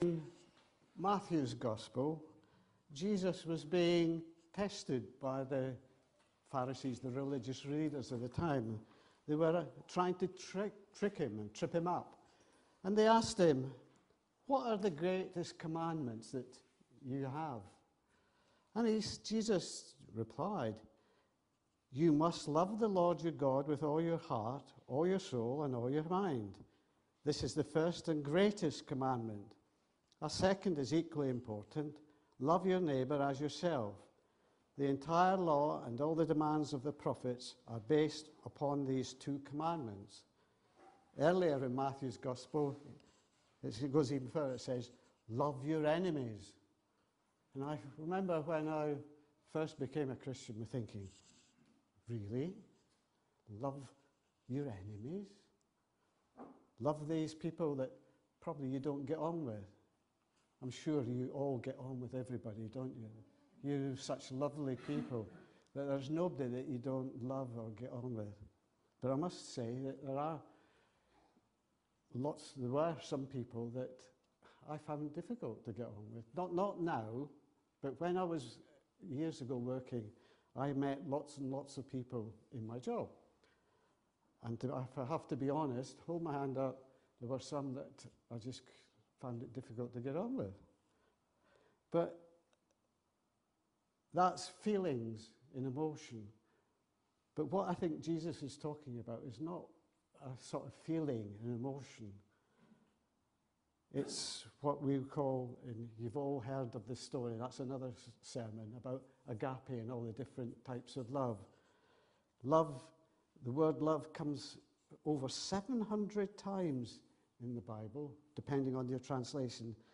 Sermon 7 December 2025